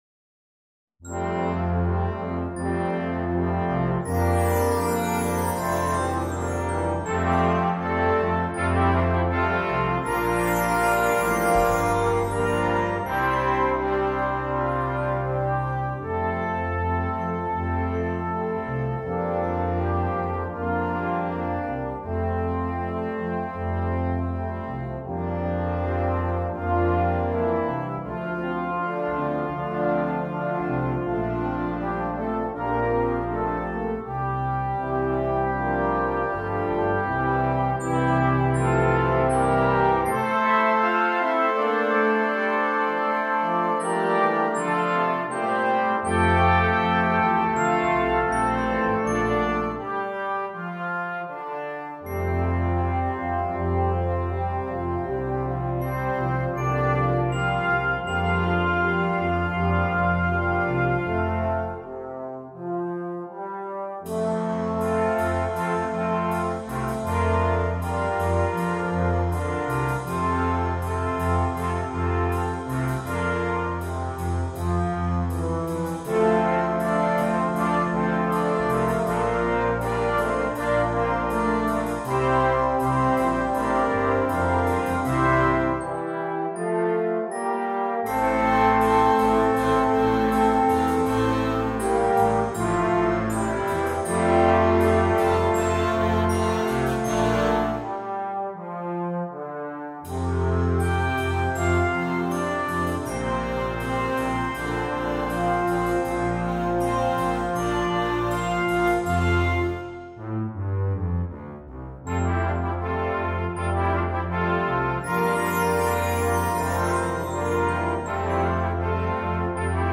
beautiful new hymn tune arrangement